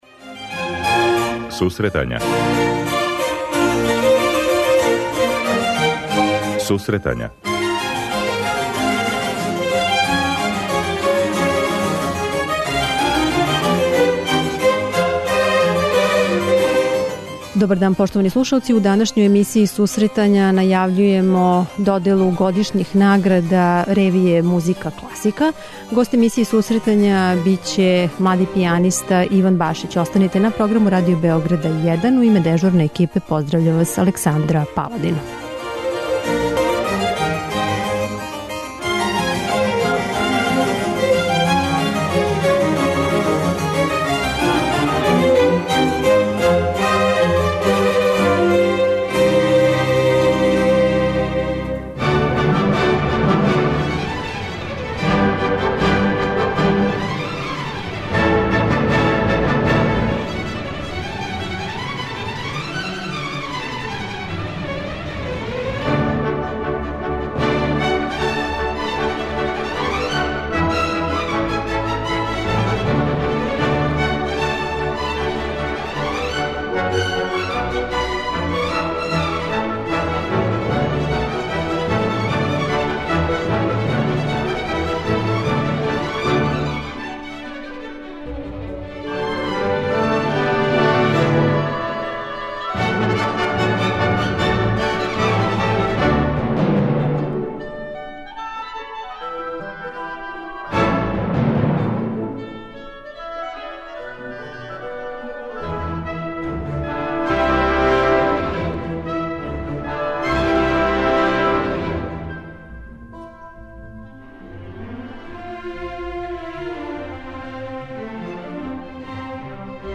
преузми : 26.17 MB Сусретања Autor: Музичка редакција Емисија за оне који воле уметничку музику.